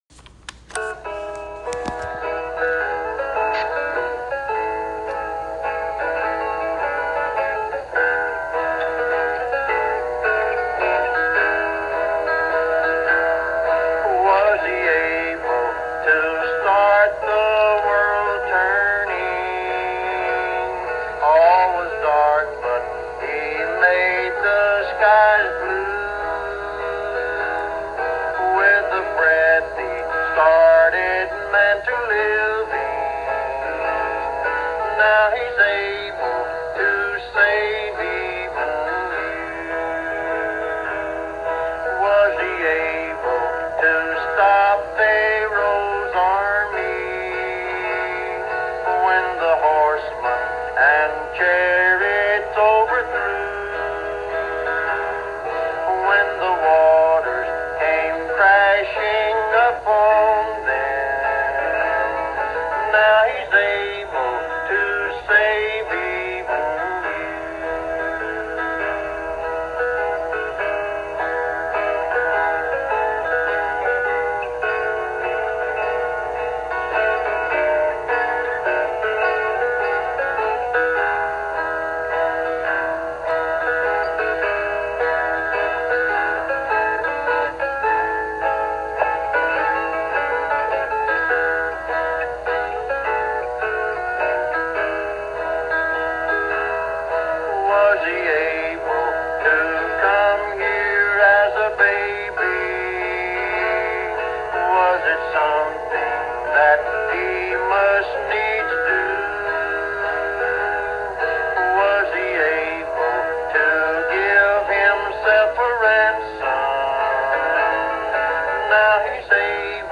In this heartfelt evangelistic sermon